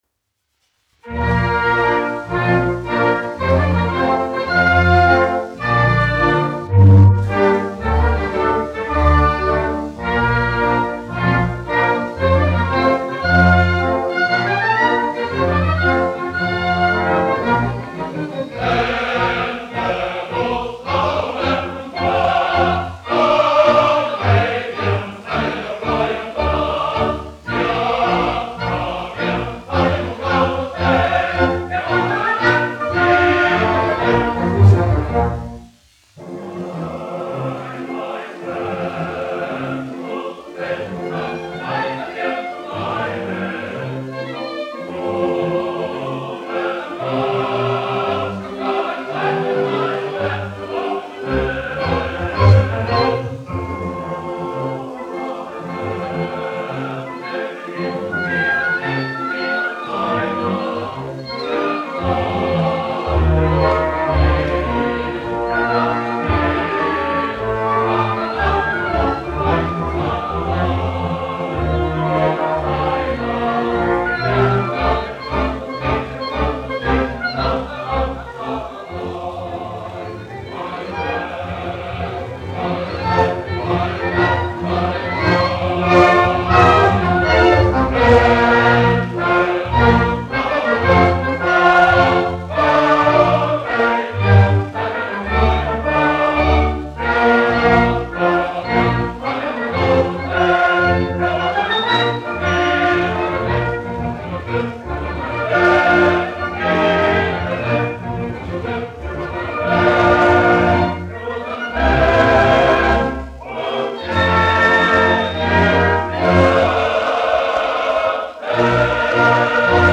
Teodors Reiters, 1884-1956, diriģents
Latvijas Nacionālā opera. Koris, izpildītājs
Latvijas Nacionālās operas orķestris, izpildītājs
1 skpl. : analogs, 78 apgr/min, mono ; 25 cm
Operas--Fragmenti